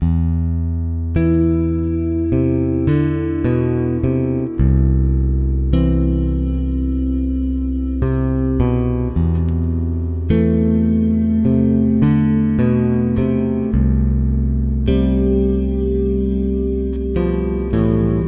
基本的热带豪宅低音
描述：低音，以配合简单的和弦进展。
标签： 105 bpm Dance Loops Bass Guitar Loops 3.08 MB wav Key : Unknown
声道立体声